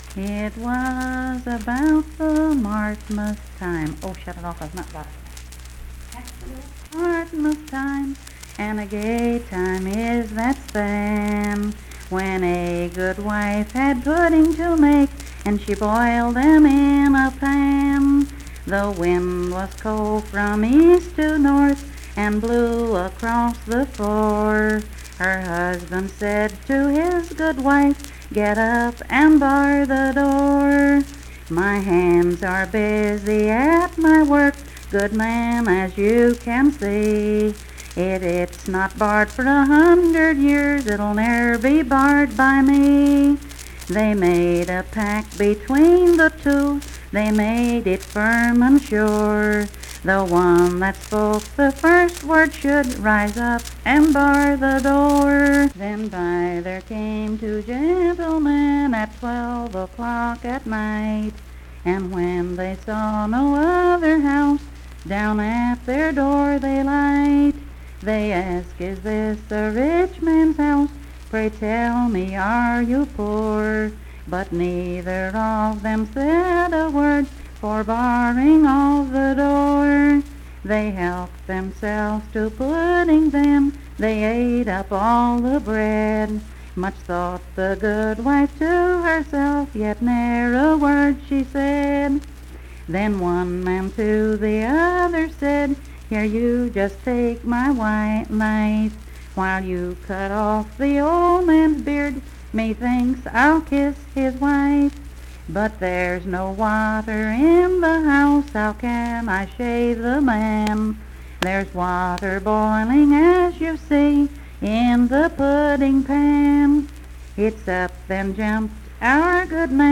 Unaccompanied vocal music performance
Verse-refrain 11(4).
Voice (sung)